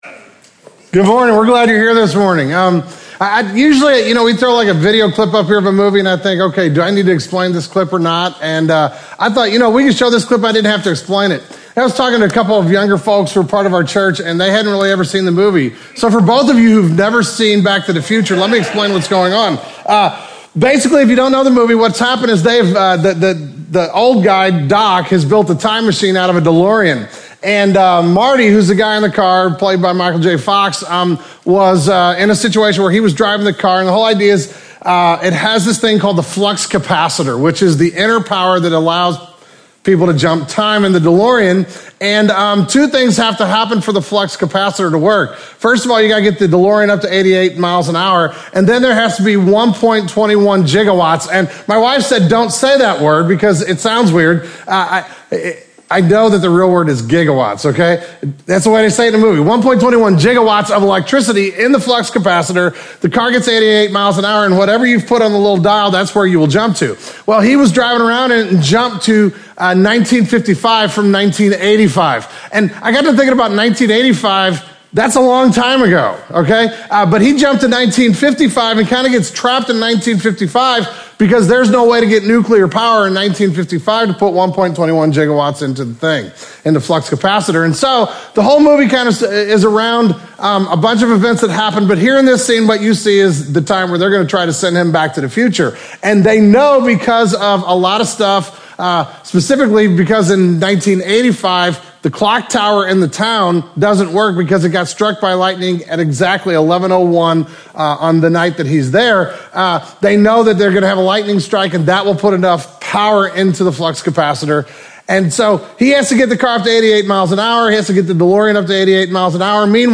In the second sermon on the church we will take a look at how God provides the power of God for his church and the things He will accomplish through a people who are submitted to and empowered by Him.